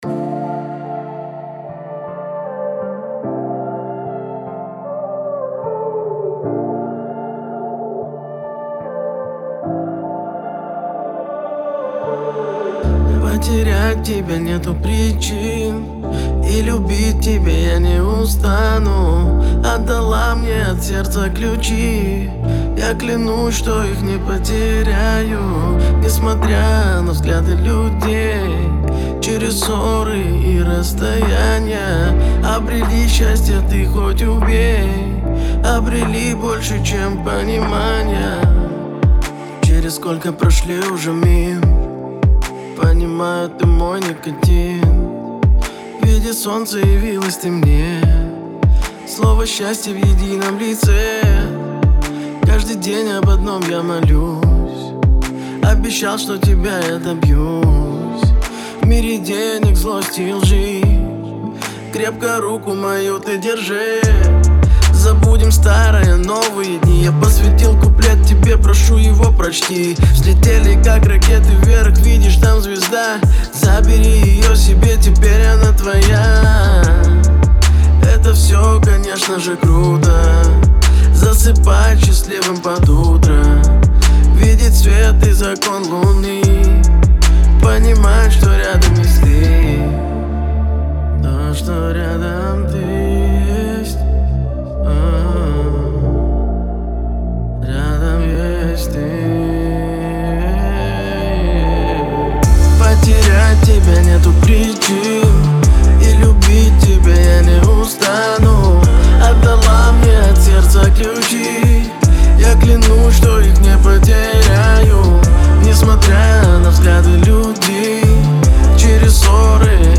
Особенностью звучания является мелодичный вокал